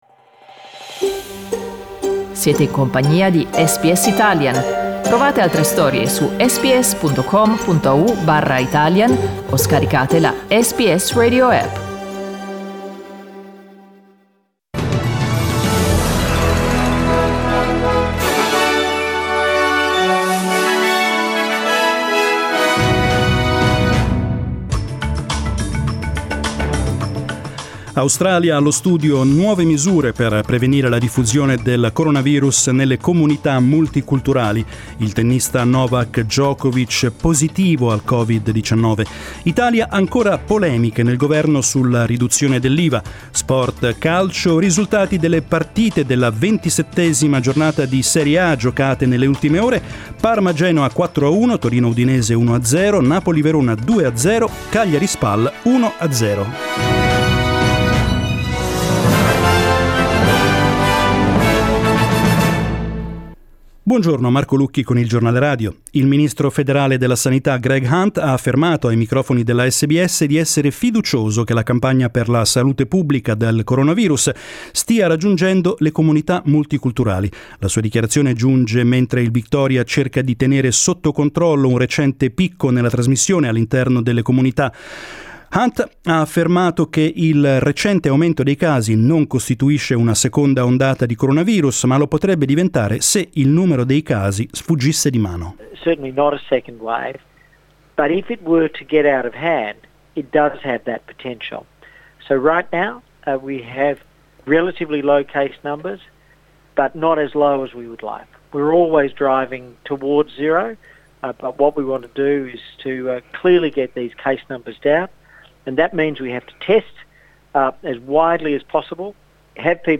News in Italian - 24 June 2020